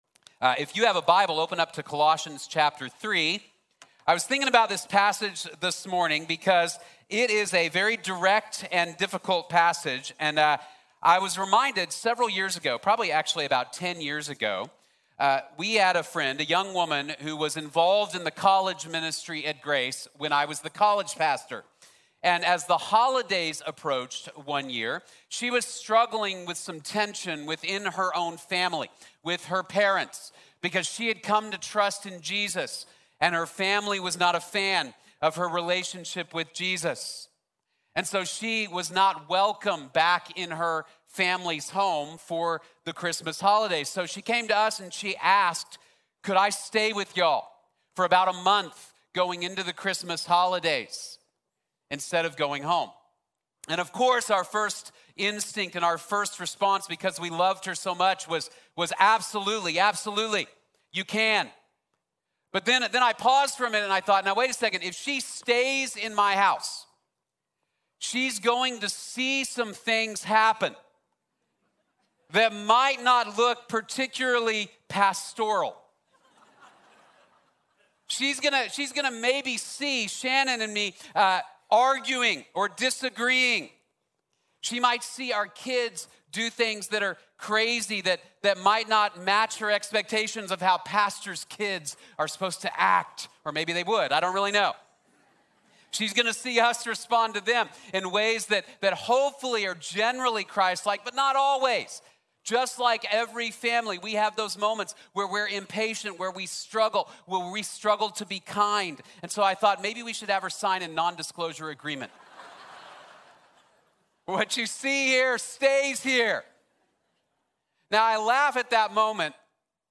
Transformed Families | Sermon | Grace Bible Church